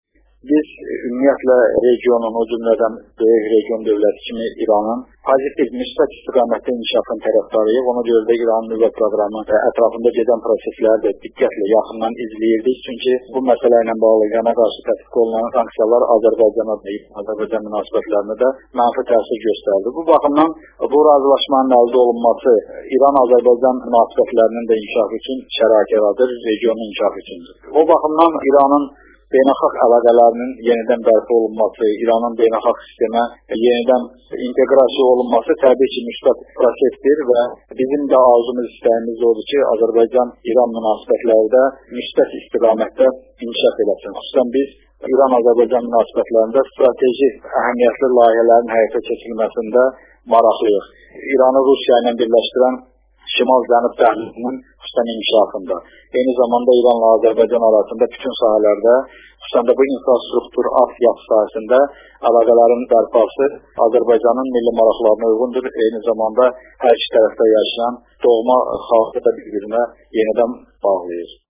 eksklüziv müsahibədə